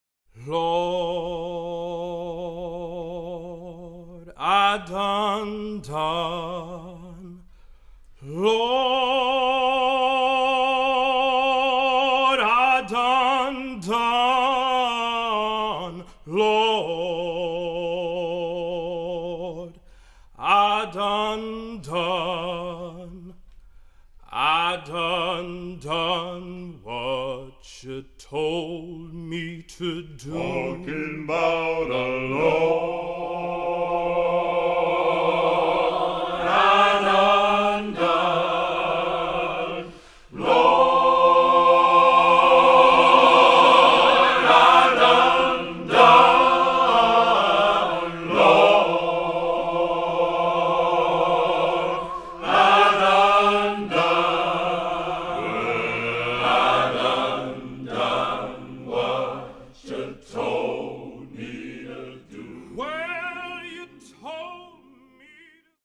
Voicing: SATBB